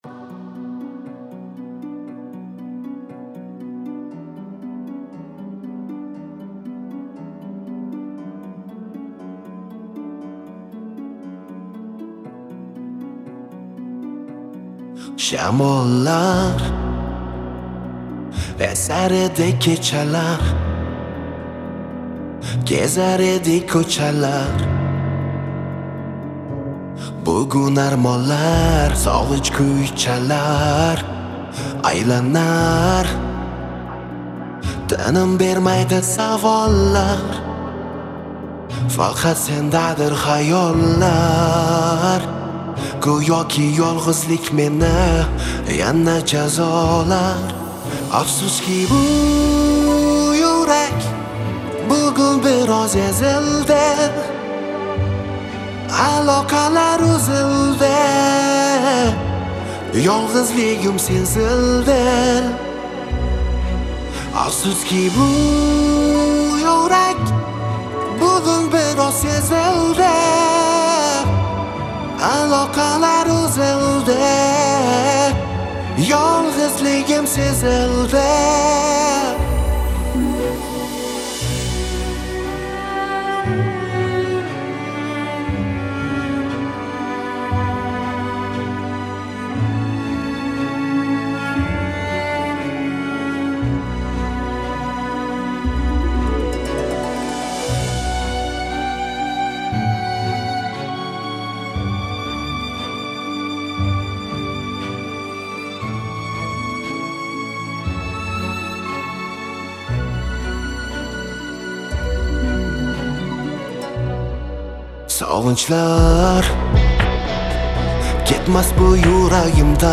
Жанр: Узбекские